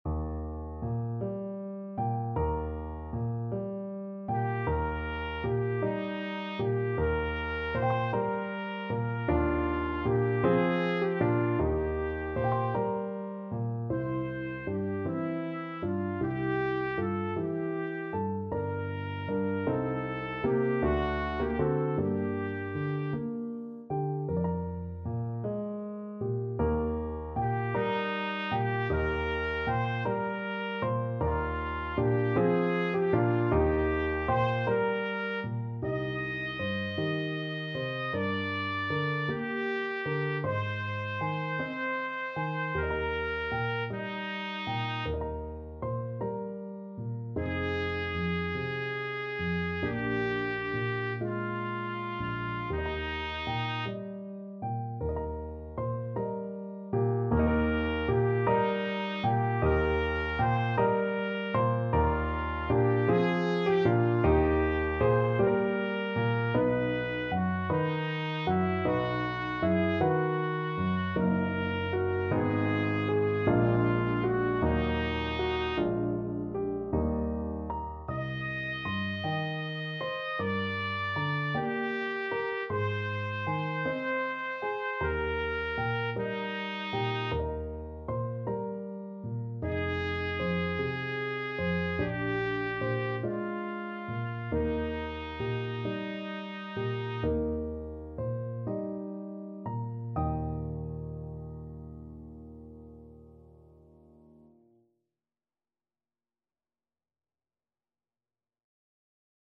Trumpet
Eb major (Sounding Pitch) F major (Trumpet in Bb) (View more Eb major Music for Trumpet )
6/8 (View more 6/8 Music)
~. = 52 Allegretto
Classical (View more Classical Trumpet Music)
reger_maria_wiegenlied_TPT.mp3